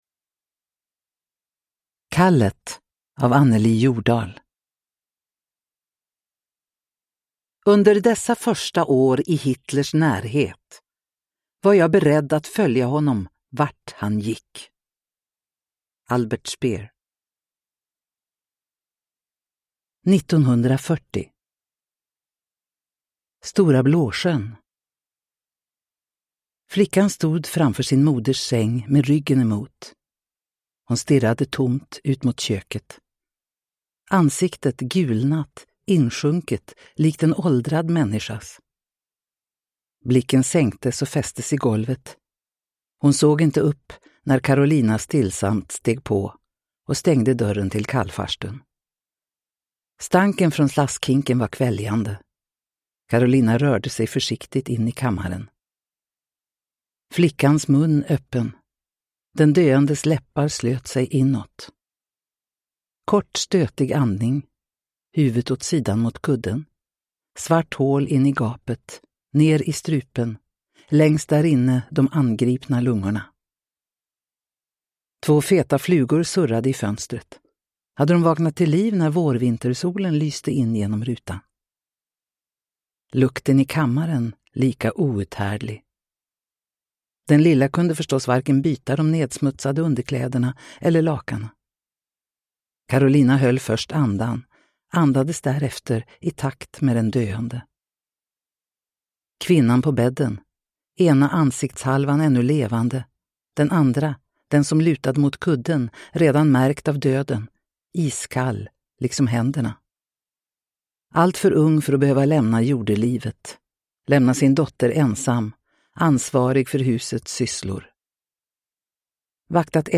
Kallet (ljudbok) av Anneli Jordahl